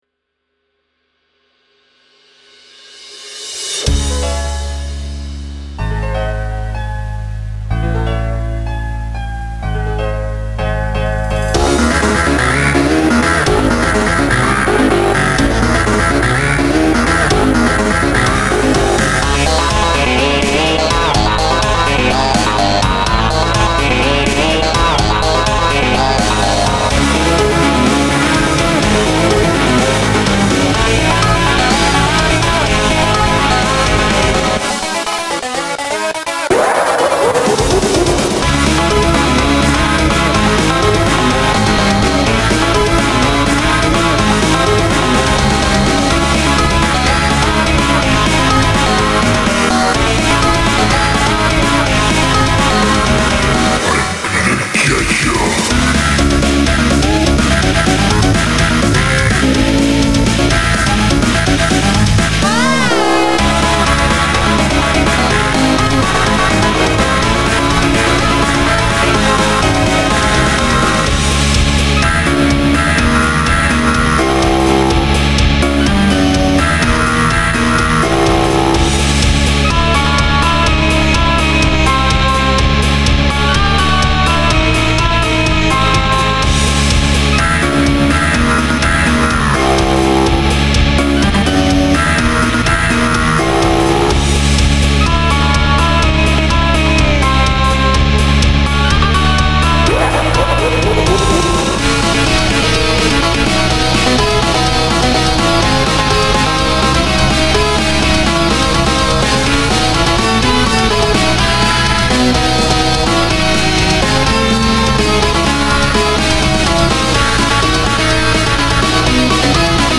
is in the key of C sharp/D flat.
The voicelines for Sonic.exe here were recorded by myself.
An FNF Remix